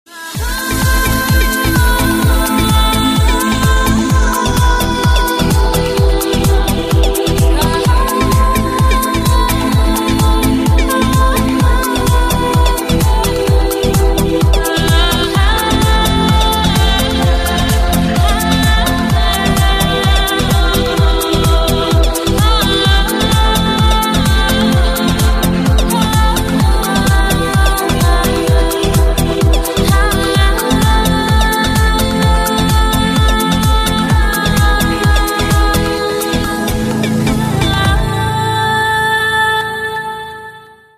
• Качество: 128, Stereo
громкие
восточные мотивы
Electronic
спокойные
красивая мелодия
красивый женский голос
house